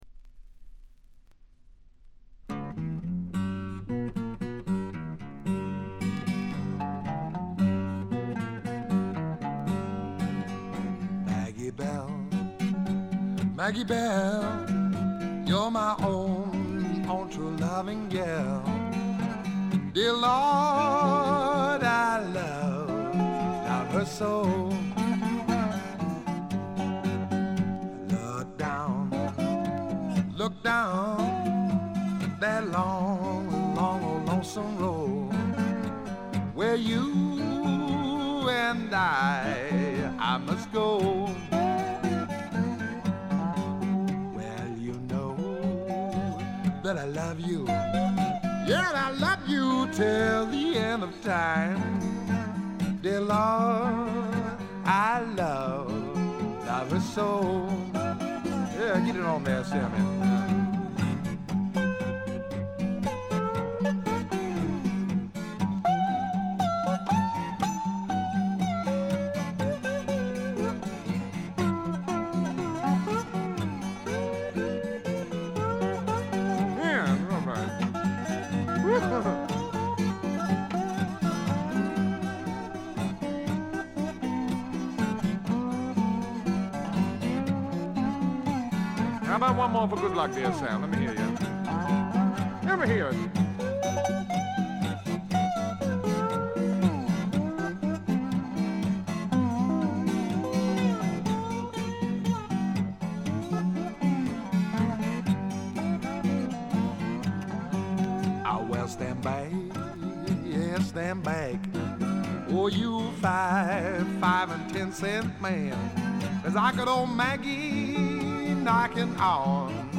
試聴曲は現品からの取り込み音源です。
※終盤で大きめのプツ音が出ますが再クリーニング後は出なくなりました